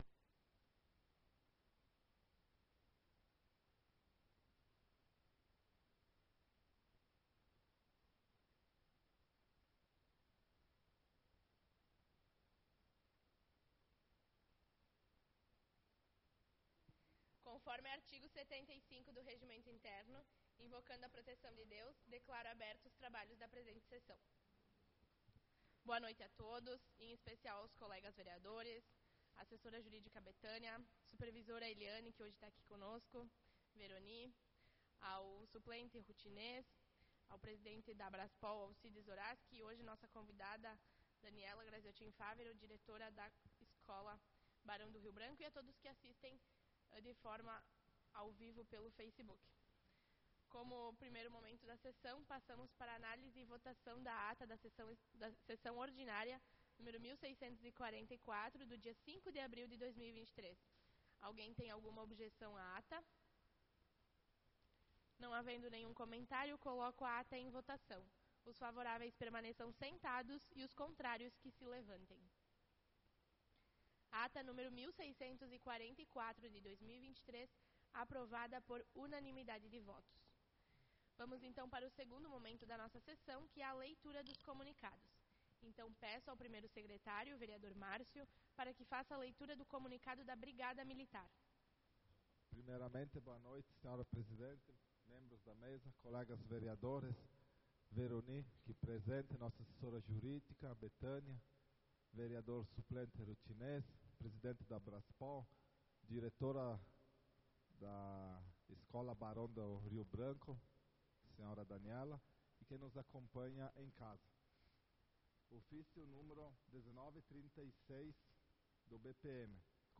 Sessão Ordinária do dia 12/04/2023